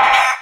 leap1.wav